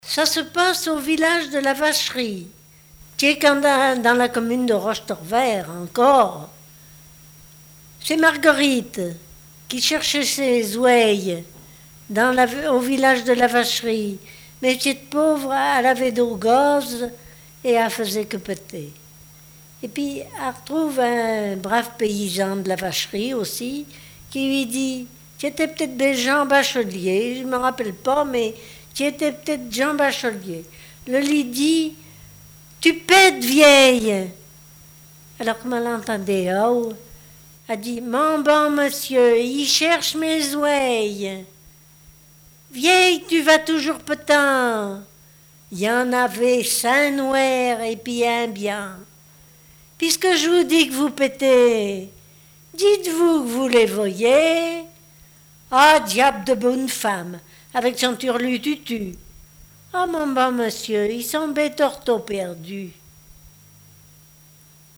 Patois local
Genre sketch
chansons et historiettes
Catégorie Récit